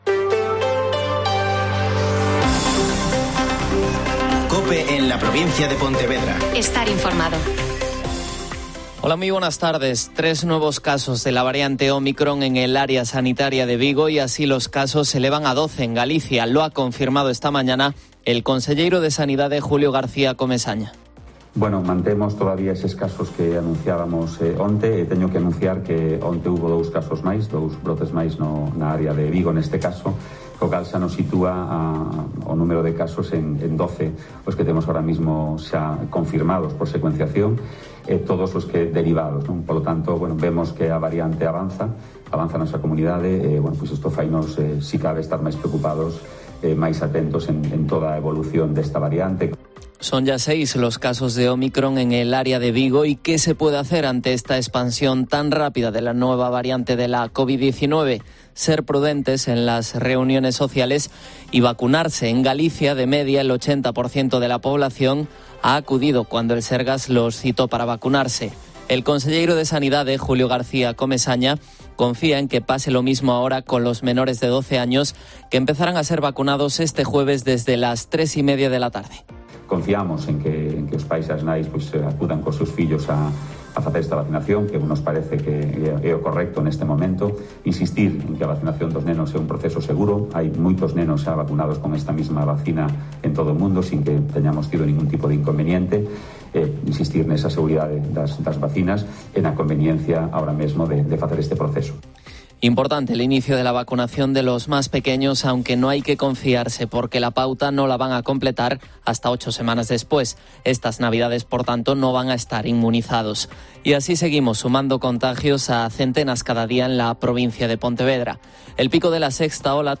INFORMATIVO COPE EN LA PROVINCIA DE PONTEVEDRA 14-12-21